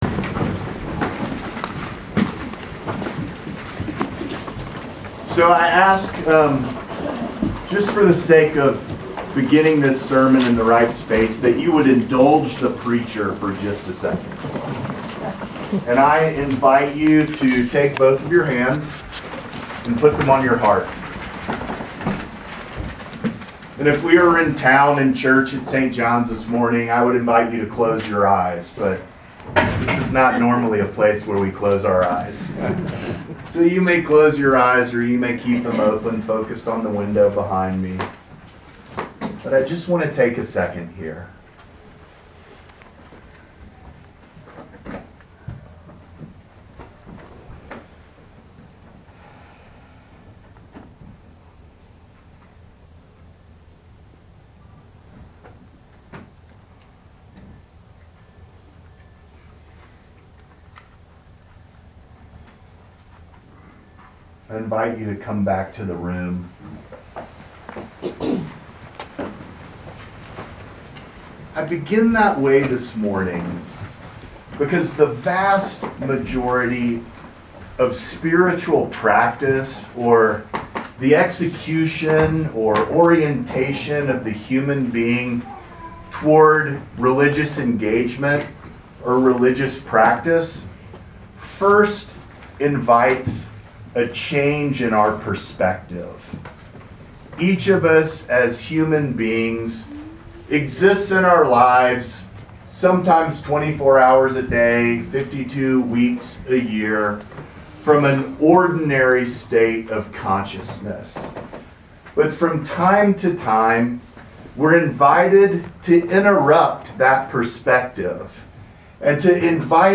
Proper 12 at Chapel of the Transfiguration
Sermons